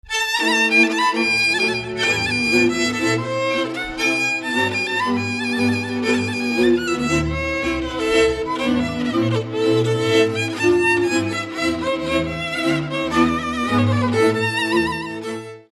Dallampélda: Hangszeres felvétel
Erdély - Szilágy vm. - Szilágybagos
Műfaj: Lassú csárdás
Stílus: 6. Duda-kanász mulattató stílus